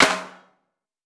TC SNARE 22.wav